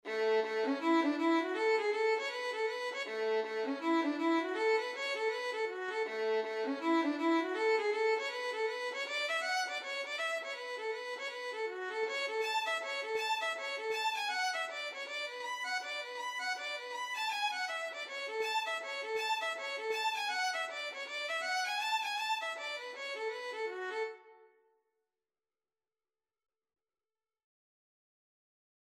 A major (Sounding Pitch) (View more A major Music for Violin )
4/4 (View more 4/4 Music)
Violin  (View more Intermediate Violin Music)
Traditional (View more Traditional Violin Music)
Reels
Irish
keeper_hill_ON1481_vln.mp3